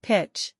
pich